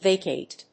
/véɪkeɪt(米国英語), vəkéɪt(英国英語)/